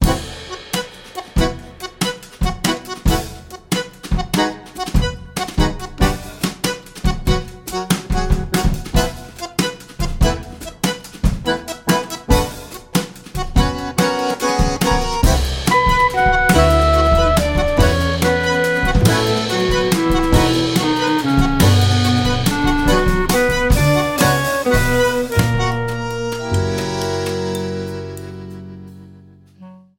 clarinet, bass clarinet, tenor saxophone
electric guitar
double bass
drums
bandoneón on tracks 1
Recorded on April 15, 2018, at Tracking Room, Amsterdam.